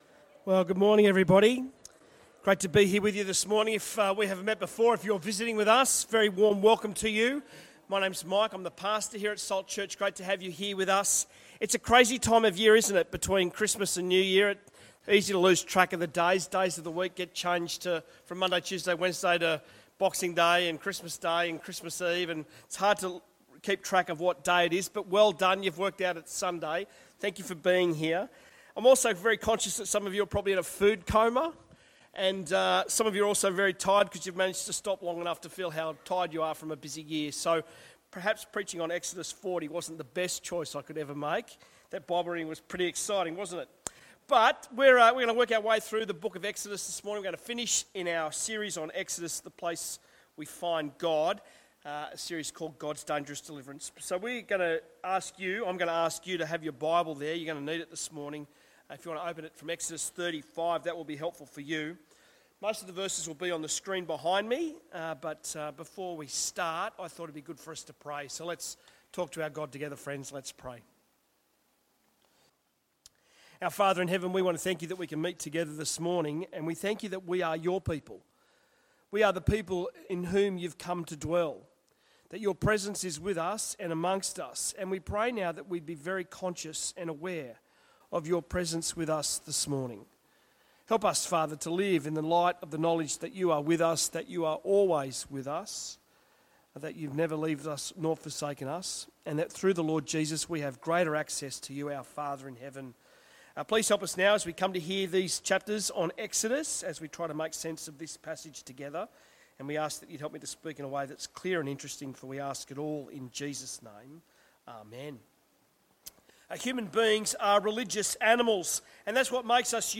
Sermons
Listen to our sermons from Sunday here
Bible talk on Exodus from God's Dangerous Deliverance